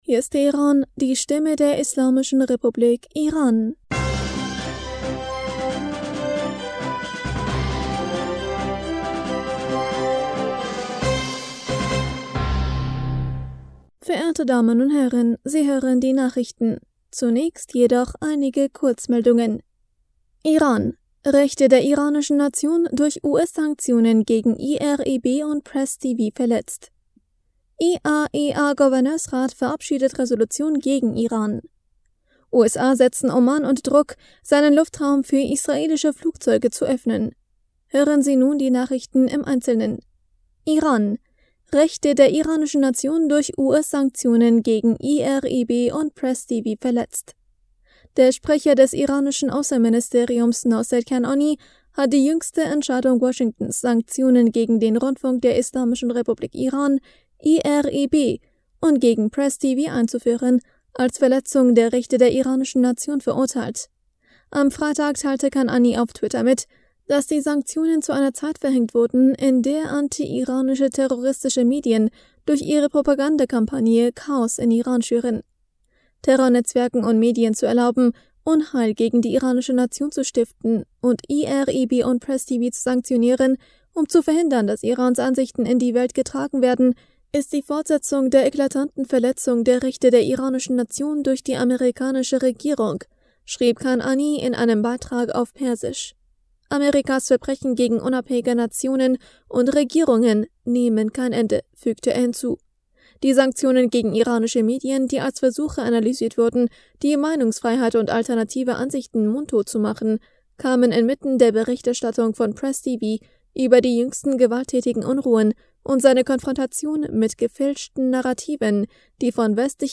Nachrichten vom 18. November 2022